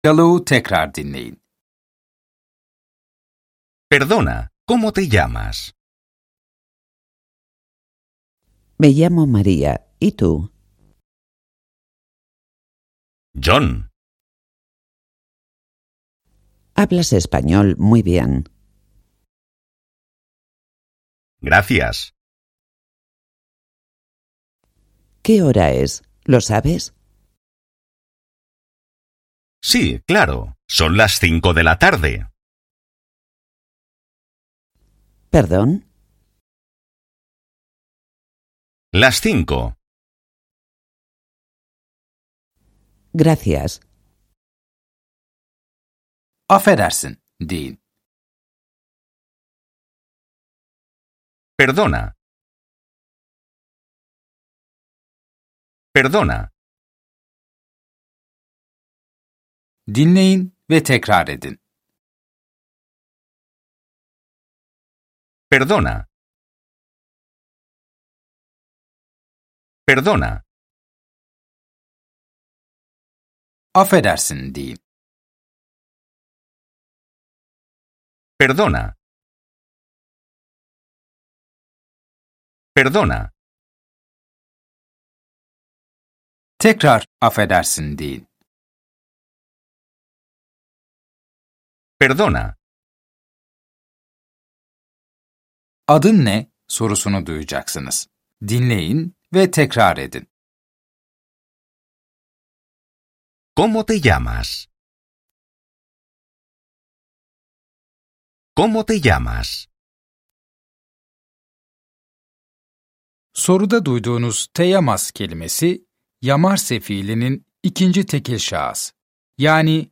Dersler boyunca sizi yönlendiren bir kişisel eğitmeniniz olacak. Ana dili İspanyolca olan iki kişi de sürekli diyalog halinde olacaklar.